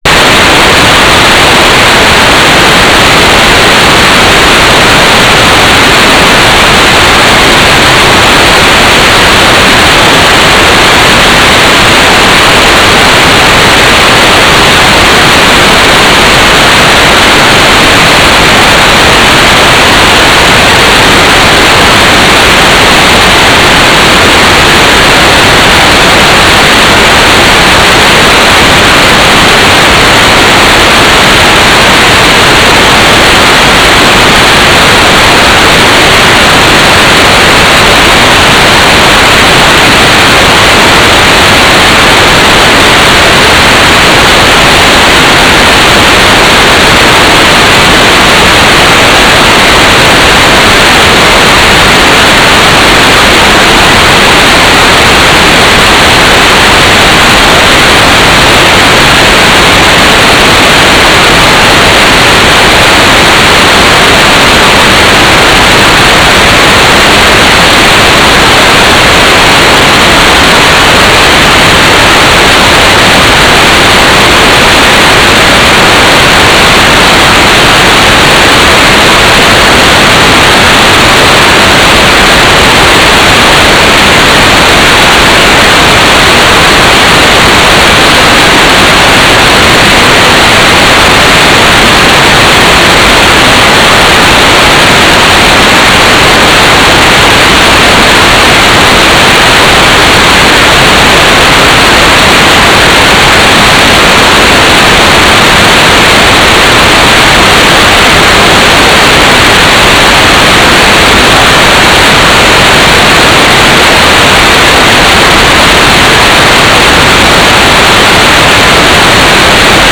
"station_name": "Mahanakorn Ground Station",
"transmitter_description": "Mode U - GMSK4k8",
"transmitter_mode": "MSK AX.100 Mode 5",